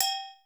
AGOGO SFT.wav